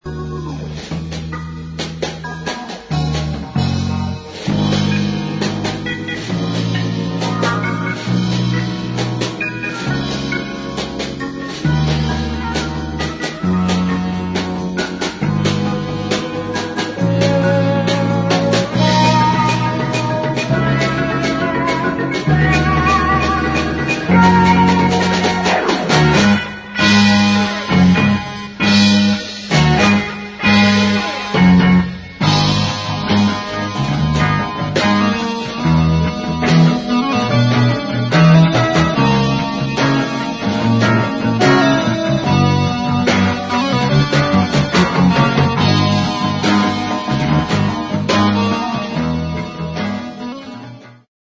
We taped the results as we went along.
Clips (mp3 medium quality stereo)